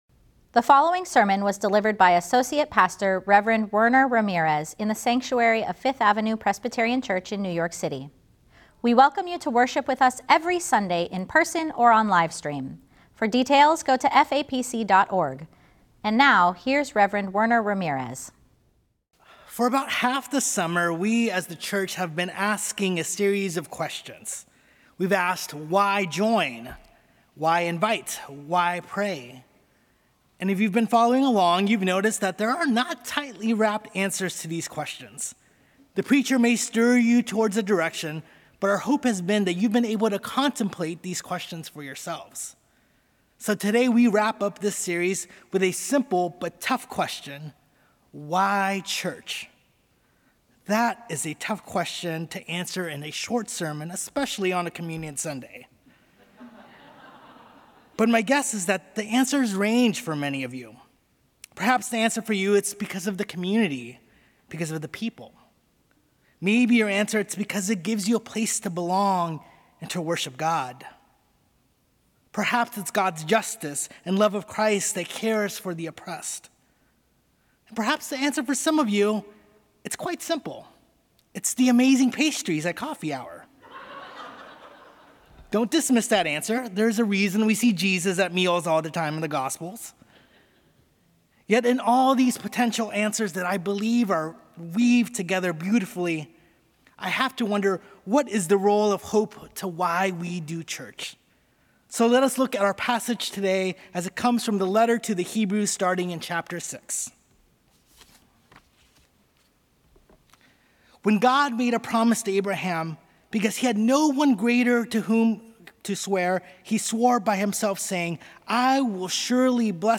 Sermon: “Why Church?”